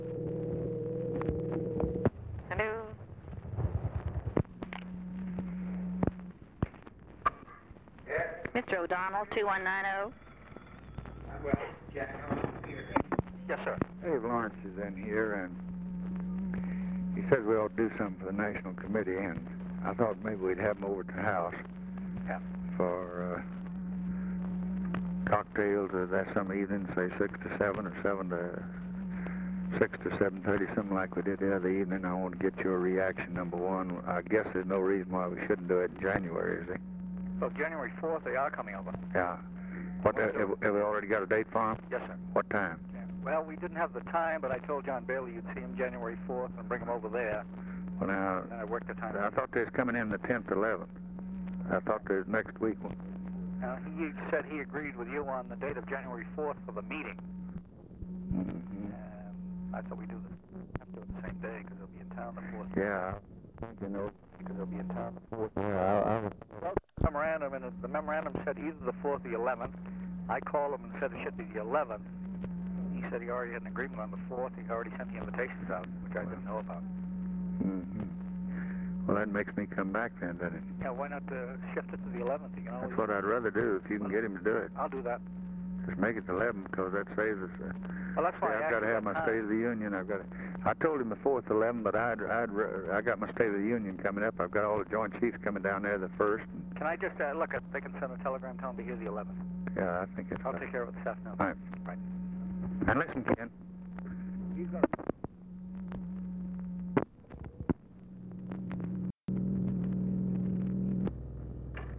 Conversation with KEN O'DONNELL, December 18, 1963
Secret White House Tapes | Lyndon B. Johnson Presidency